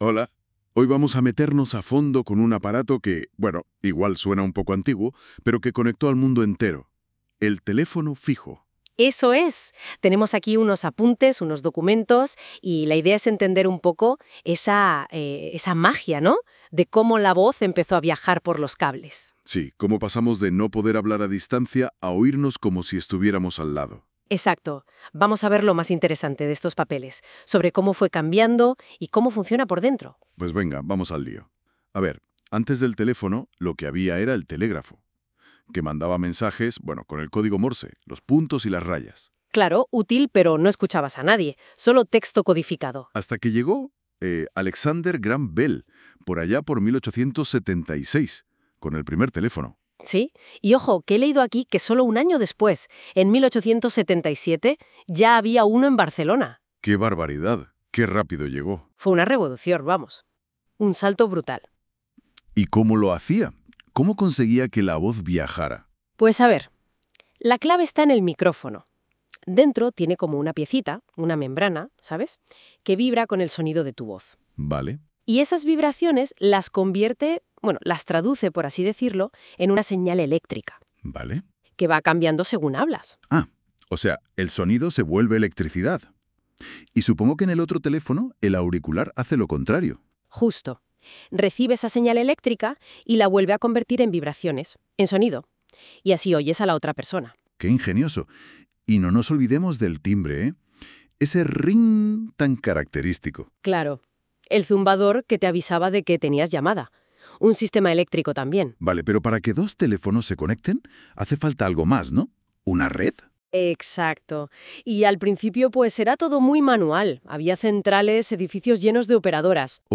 Algunos ejemplos de creación de voz con IA